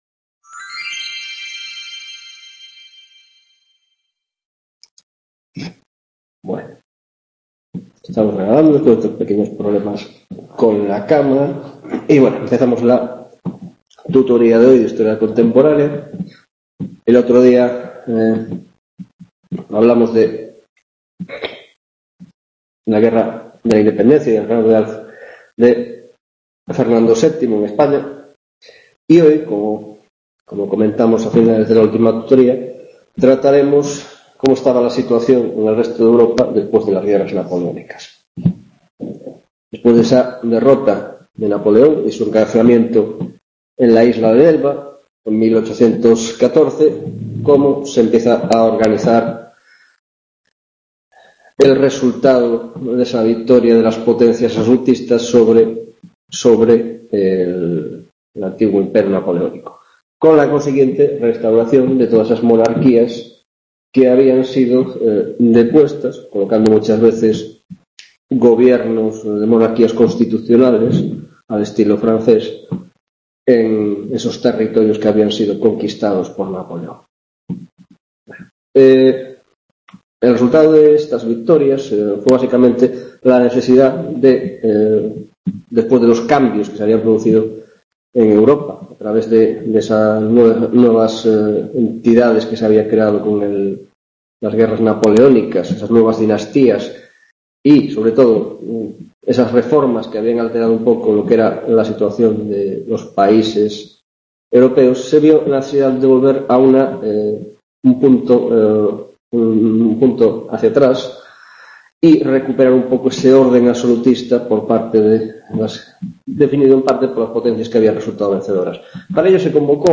6ª tutoria de Historia Contemporánea - Restauración Monárquica y Revoluciones Liberales